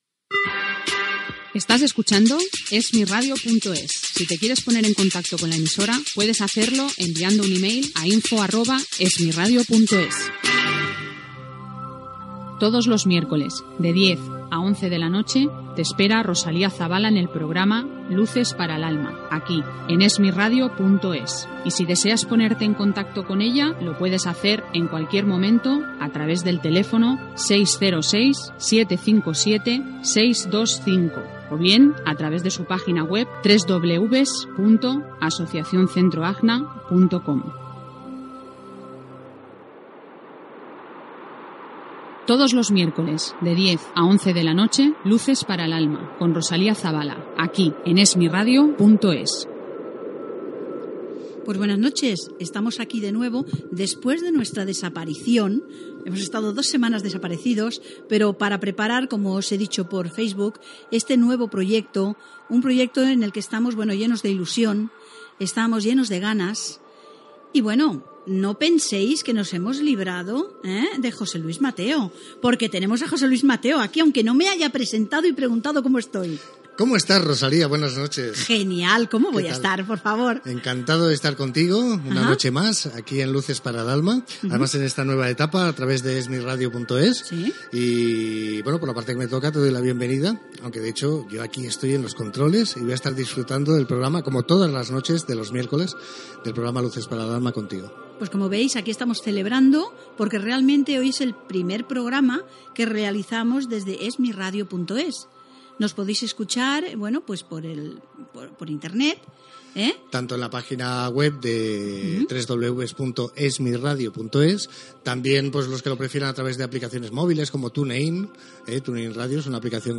Indicatiu de la ràdio, adreça electrònica, careta del programa, presentació, cursos, entrevista